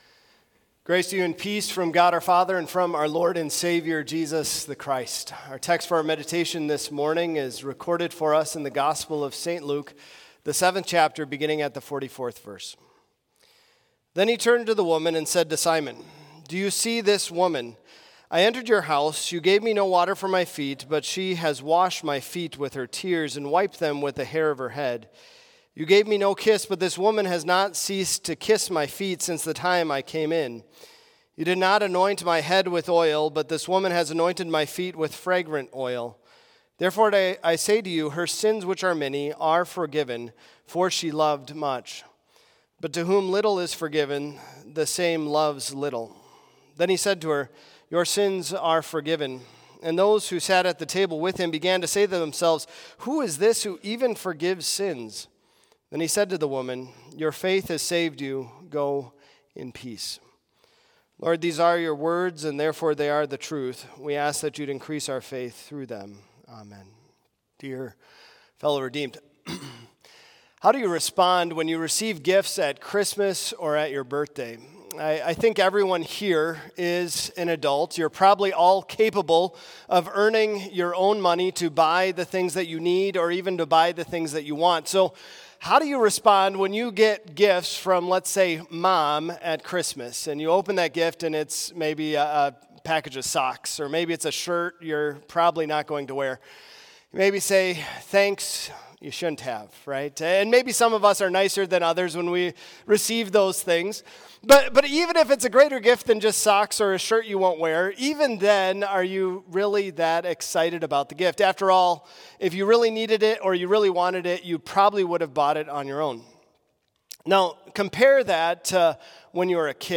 Complete service audio for Chapel - Thursday, September 26, 2024